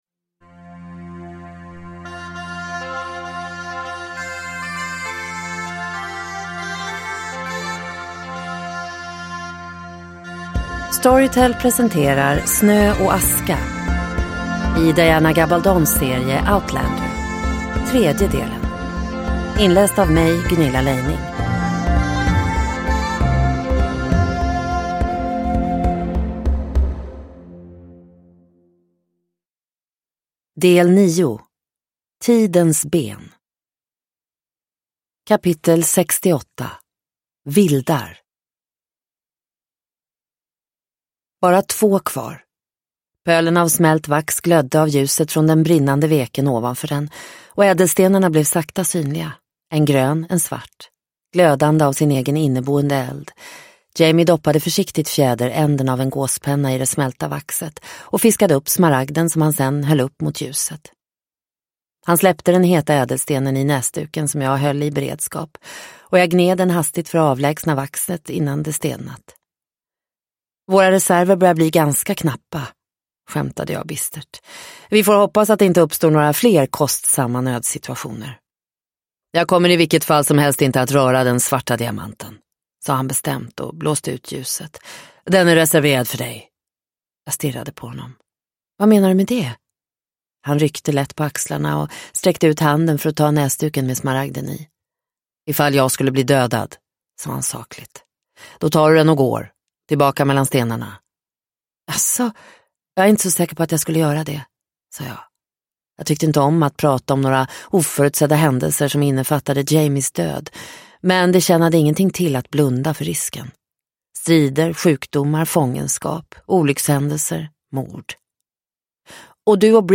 Snö och aska - del 3 – Ljudbok – Laddas ner